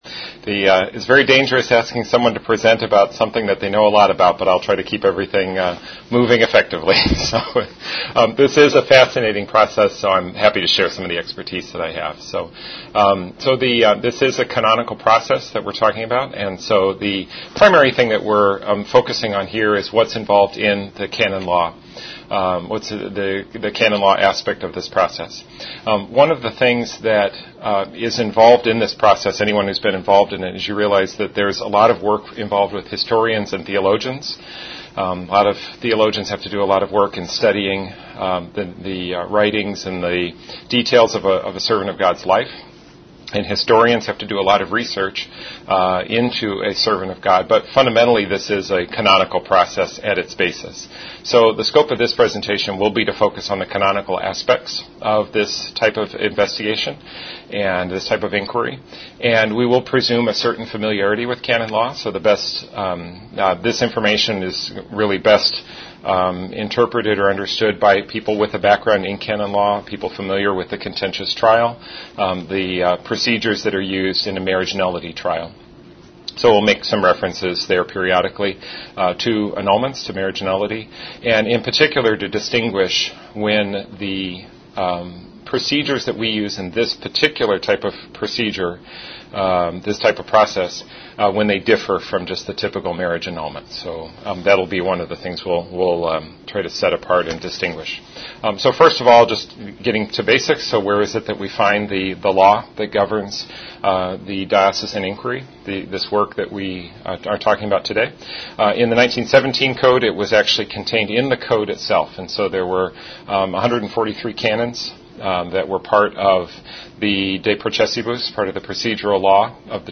The following presentation was given in 2021 for the Canon Law Society of America.